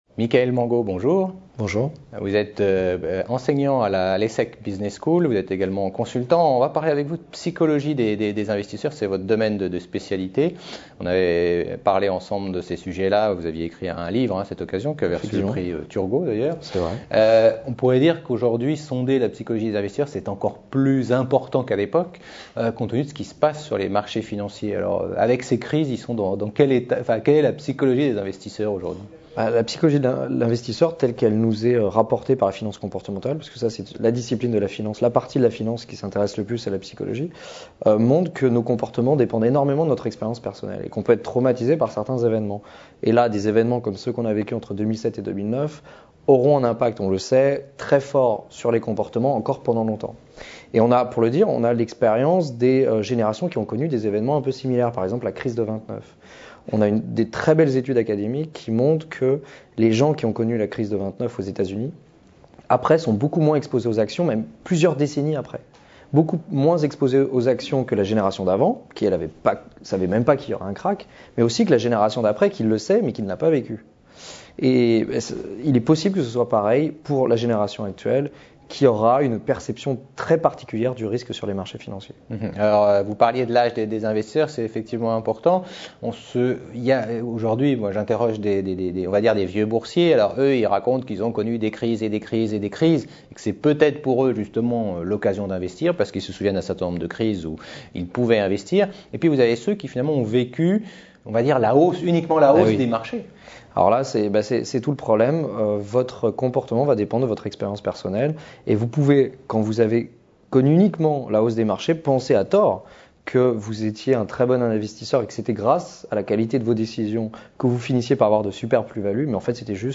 Rencontres Paris Europlace 2012 : Evolution de la psychologie des investisseurs en temps de crise